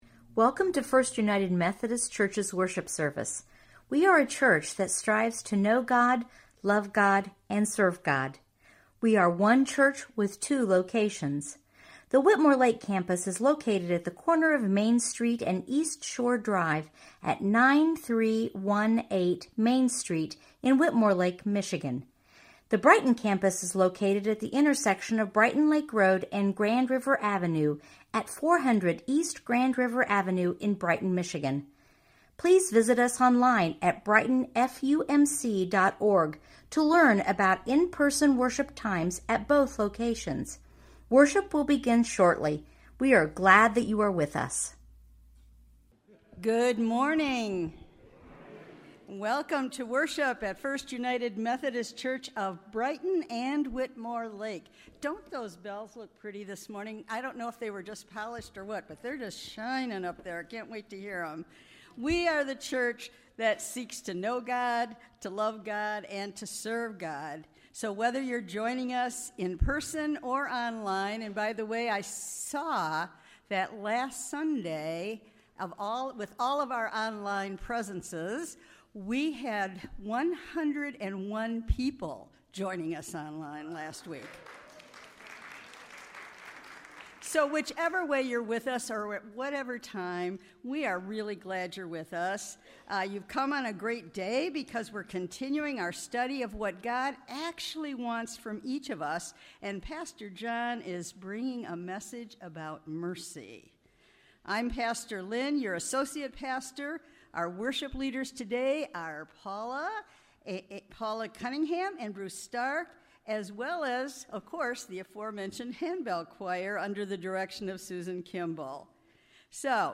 Brighton First United Methodist Church Podcasts